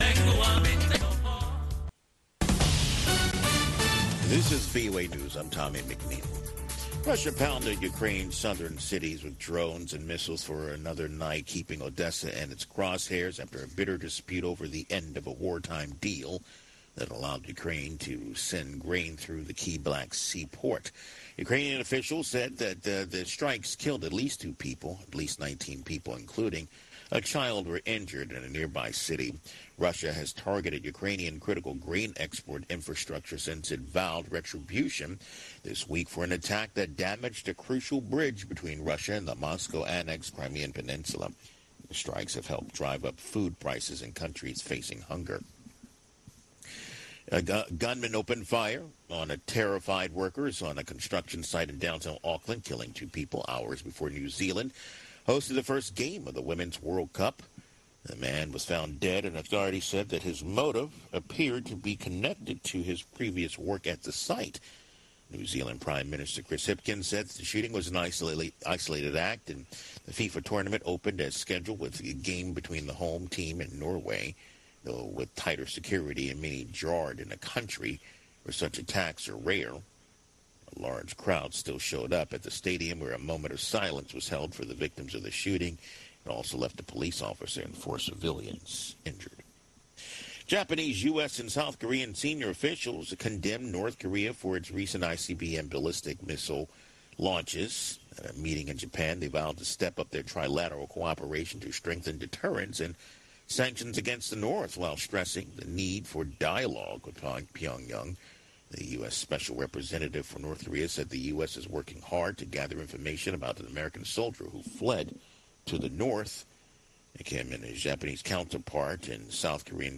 Malawi’s Vice President wants his bail conditions eased. We’ll speak with a female presidential candidate in Liberia’s October elections. The M23 rebels in eastern DRC deny they censor the media.